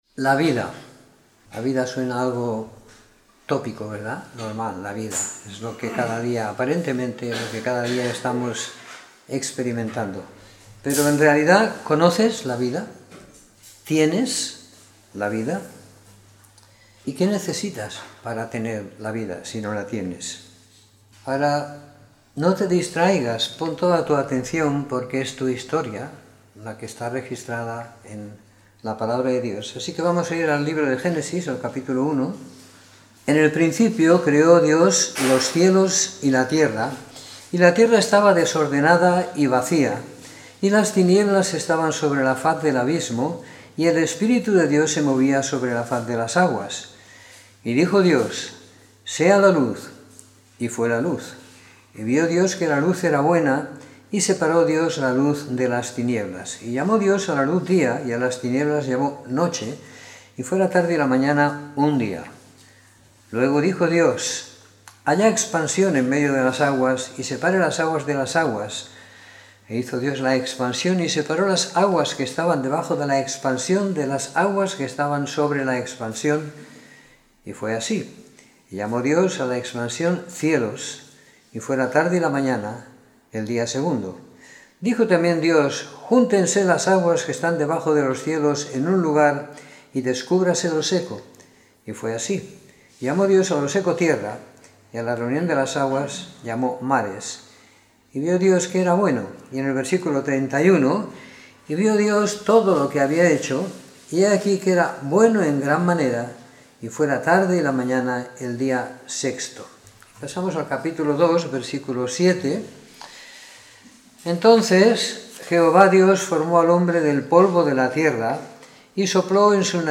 Domingo por la Mañana . 30 de Julio de 2017